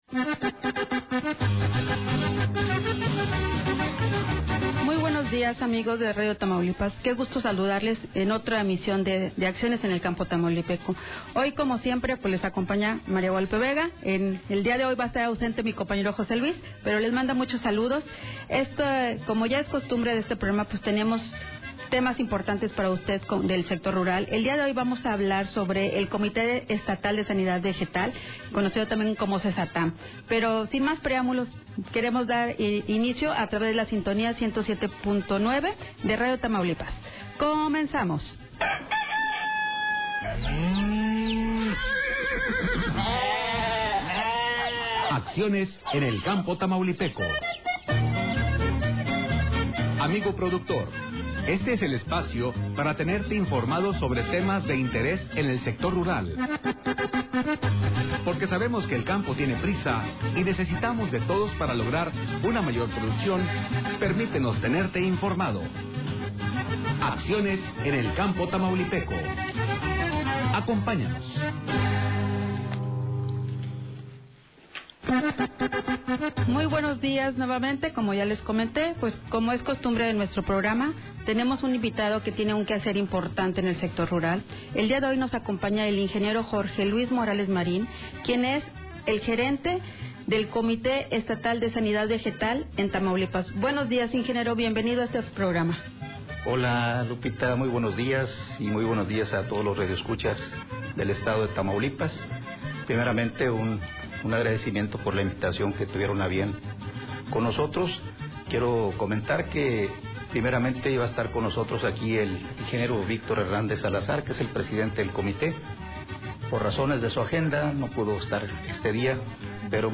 inició la entrevista explicando lo que es el Comité, así como los objetivos y funciones que realizan, como se encuentra estructurado, los programas y campañas que llevan a cabo.